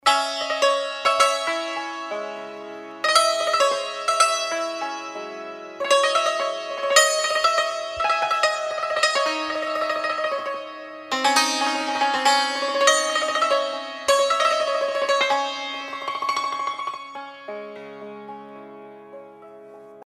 آهنگ موبایل بی کلام و سنتی(ملایم)